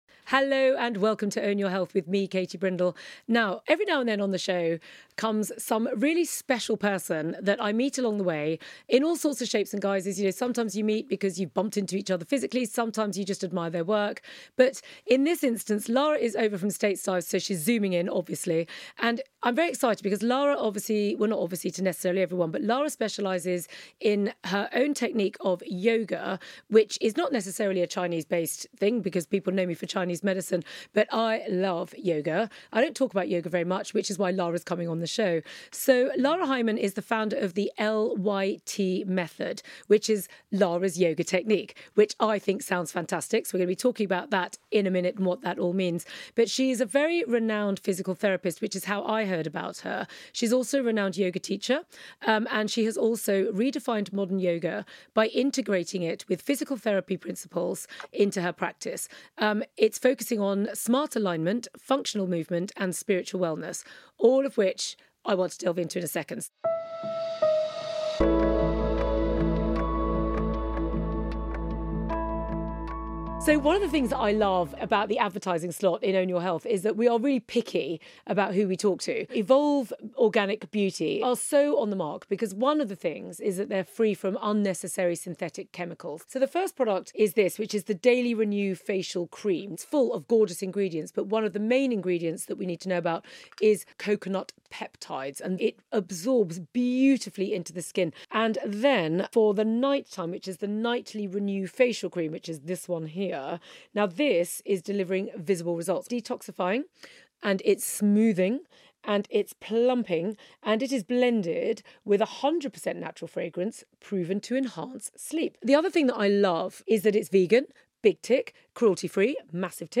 The Most Powerful Yoga Technique You’ve NEVER Tried! | Chat